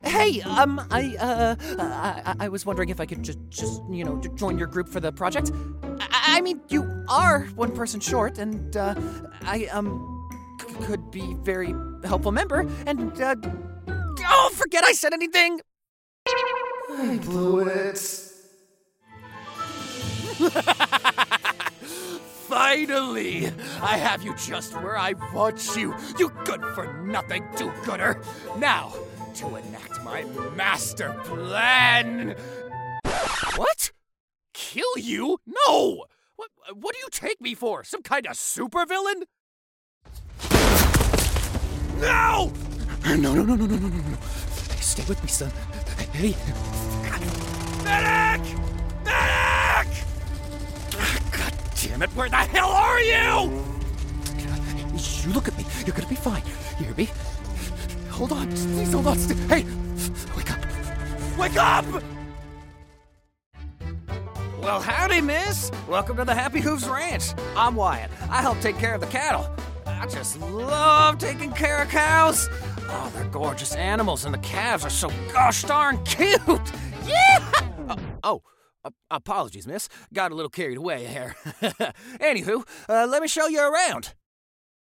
Child, Teenager, Young Adult, Adult Has Own Studio